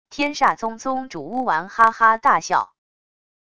天煞宗宗主巫丸哈哈大笑wav音频